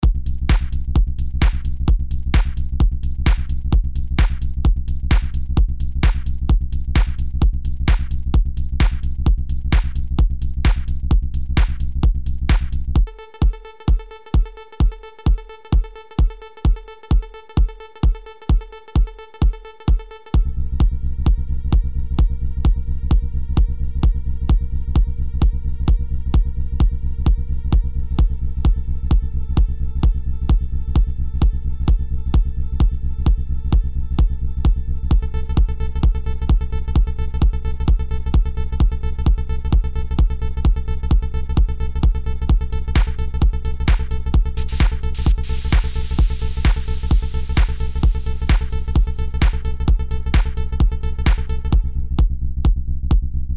Стиль: Techno